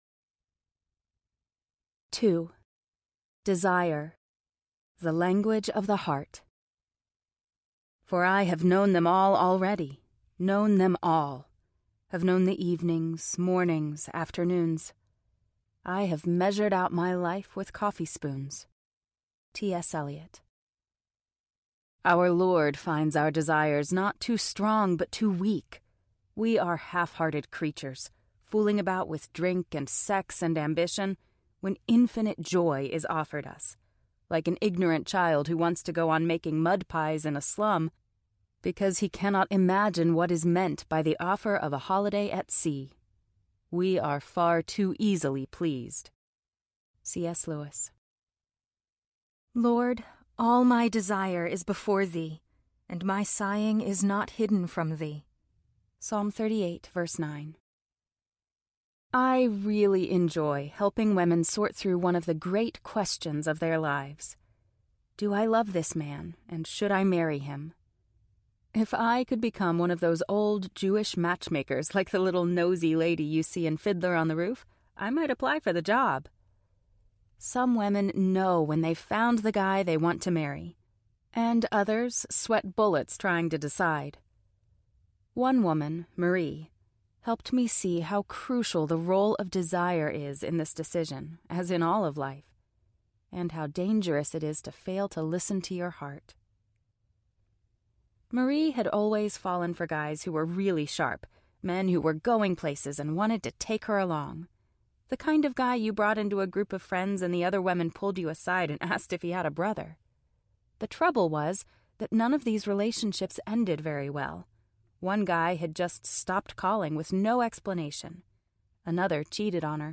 Strong Women, Soft Hearts Audiobook
5.8 Hrs. – Unabridged